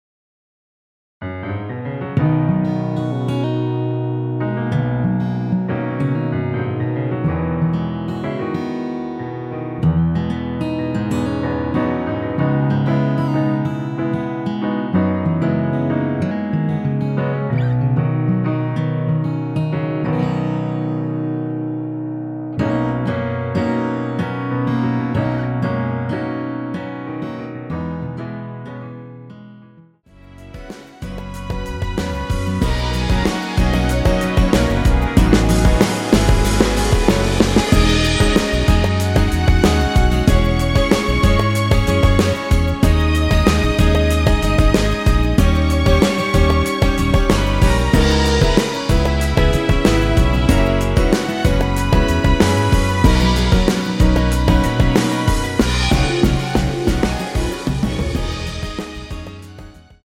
앞부분30초, 뒷부분30초씩 편집해서 올려 드리고 있습니다.